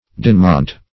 \Din"mont\